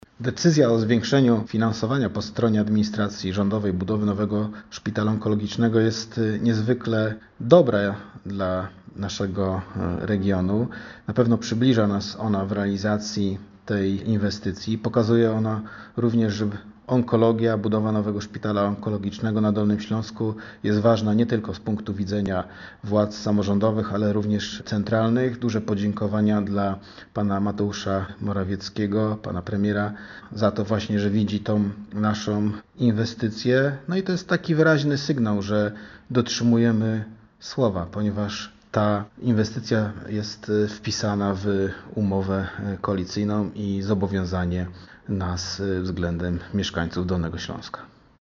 Mówi Marcin Krzyżanowski – Wicemarszałek Województwa Dolnośląskiego.